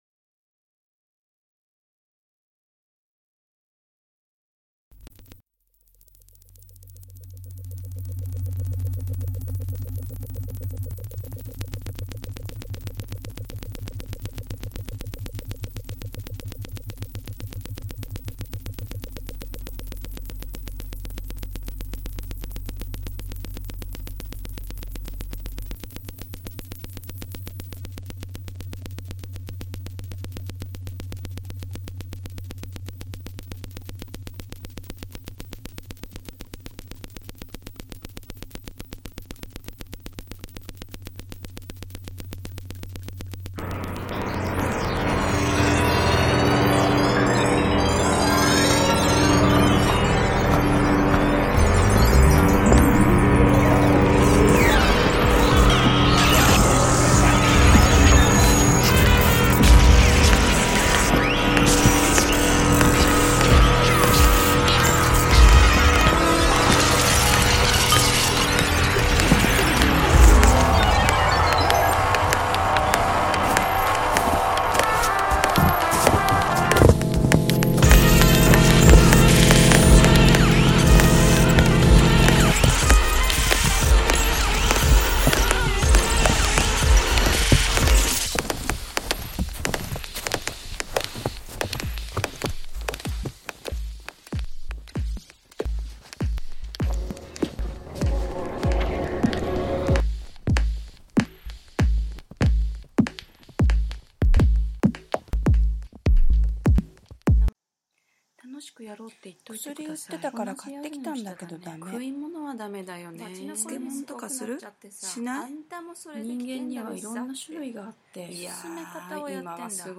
Halffloor live from Fries TV, Ebertplatz. 18:00-20:00 (CET, Cologne, local time) Play In New Tab (audio/mpeg) Download (audio/mpeg)